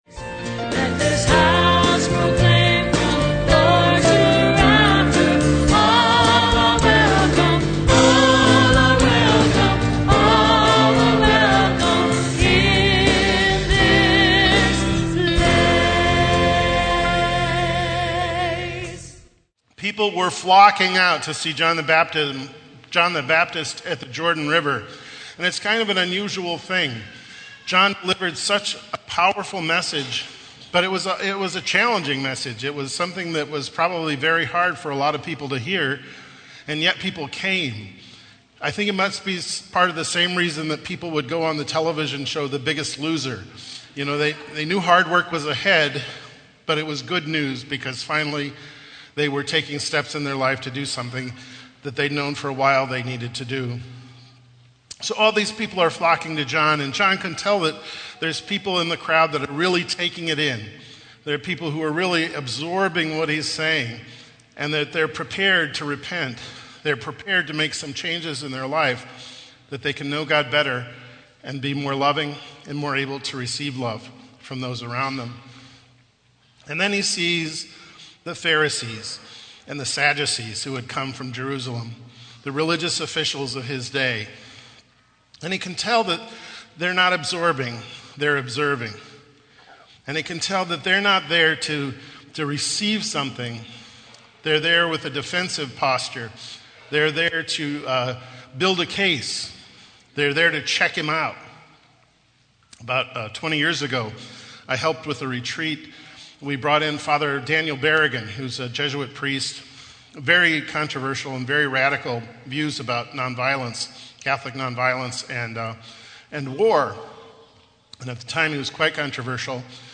Homily - 12/5/10 - 2nd Sunday Advent - St Monica Catholic Community Media Center